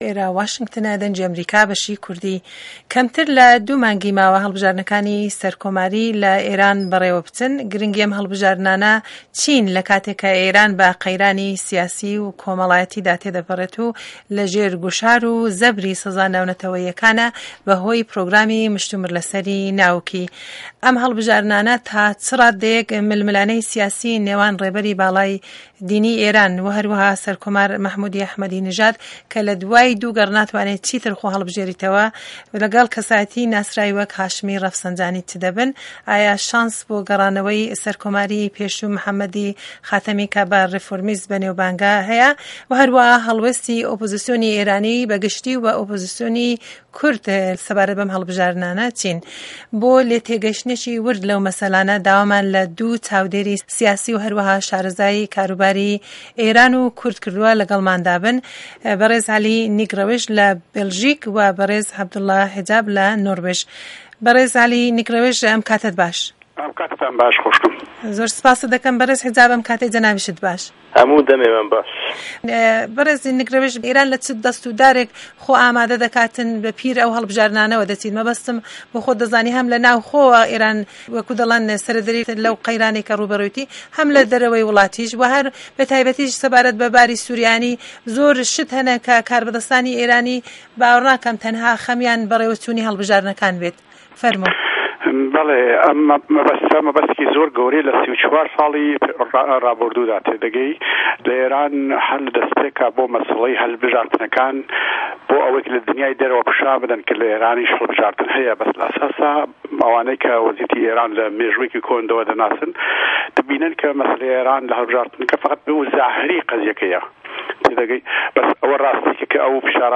مێزگرد: ئاستنگه‌کانی به‌رده‌م ئێران بۆ ڕه‌وایی دان به‌ هه‌ڵبژاردنه‌کانی سه‌رکۆماری
مێزگرد:دوو شاره‌زای کاروباری ئێران و کورد و باسی هه‌ڵبژاردنه‌کانی سه‌رکۆماری